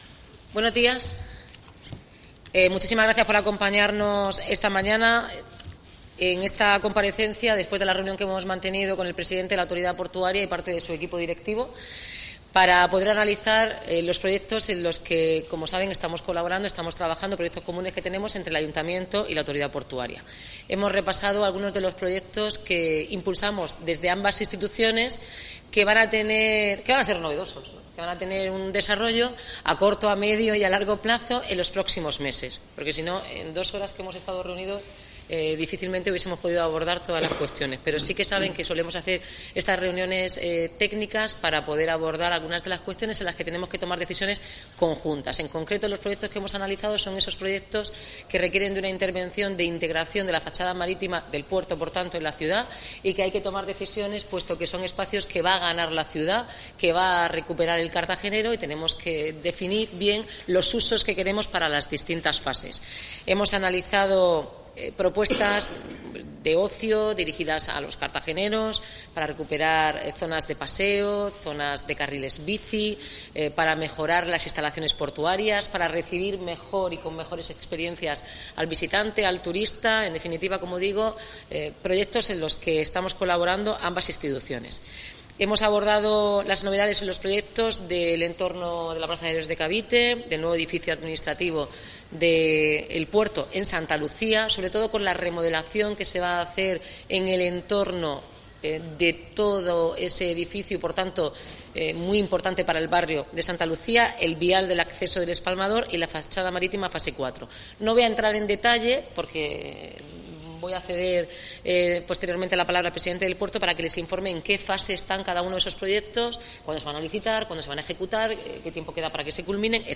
Durante la reunión técnica celebrada este jueves en el Palacio Consistorial se han repasado los proyectos comunes que saldrán a licitación los próximos ...
Enlace a Declaraciones de Noelia Arroyo y Pedro Pablo Hernández